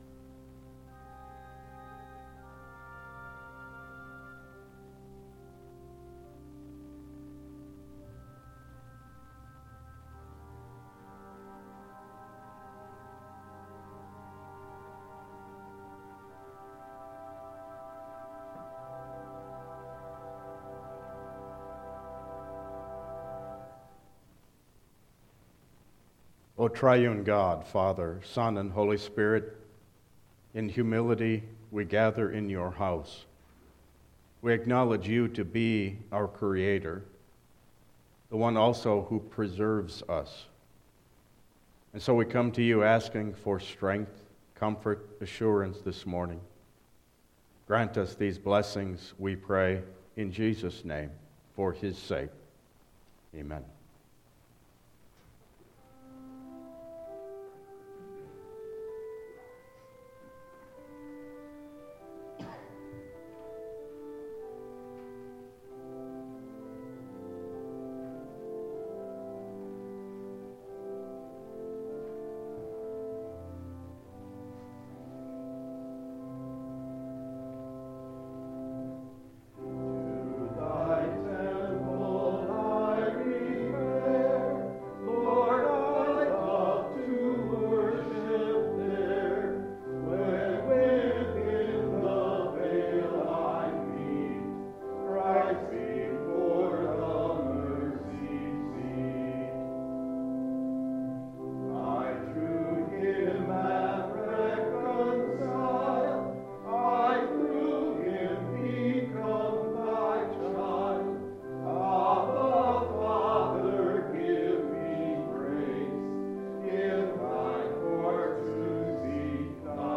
Download Files Printed Sermon and Bulletin